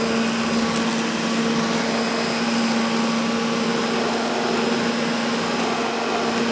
vacuum-cleaner-sound